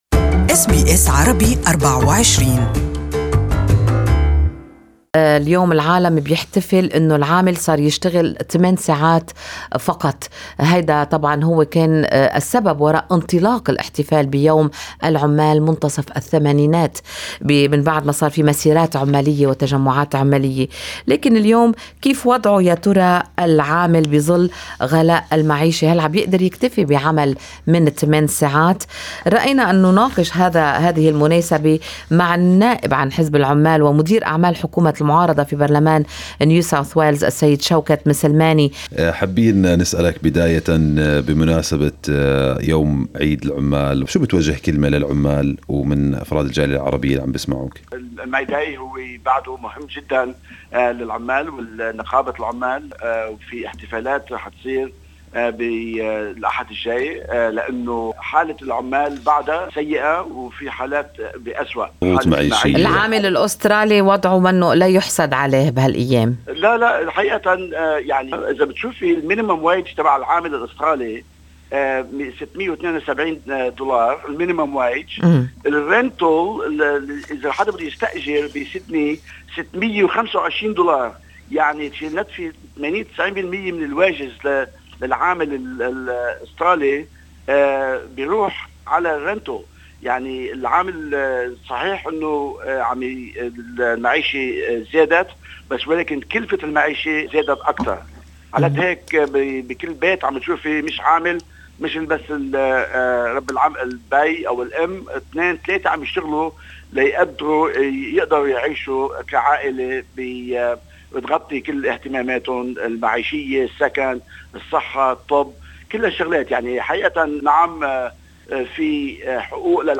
On the occasion of Labor Day that's celebrated worldwide on the first of May, Good Morning Australia interviewed Mr. Shawkat Maslamani, Opposition Whip in the Senate of NSW to discuss the challenges facing the Australian labor nowadays.